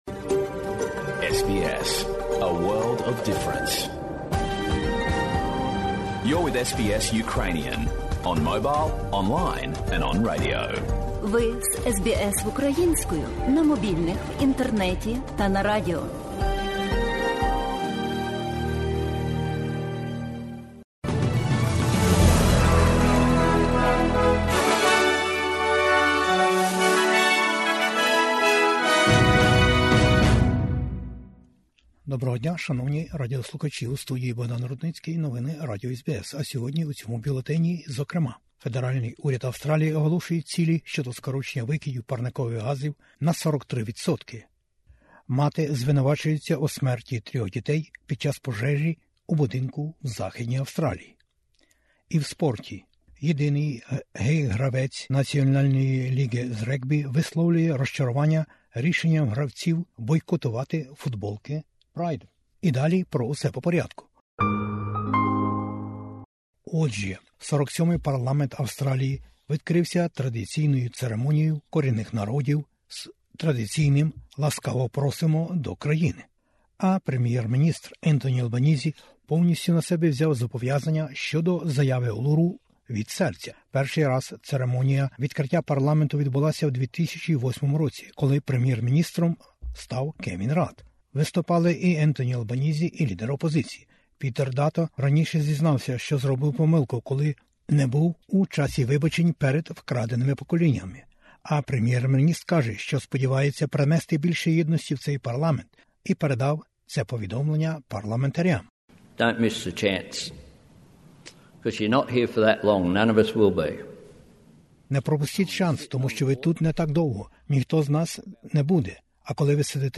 Бюлетень SBS новин українською мовою. Парламент Австралії розпочав роботу привітом корінних народів.